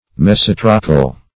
Search Result for " mesotrochal" : The Collaborative International Dictionary of English v.0.48: Mesotrochal \Mes"o*tro`chal\, a. [Meso- + Gr.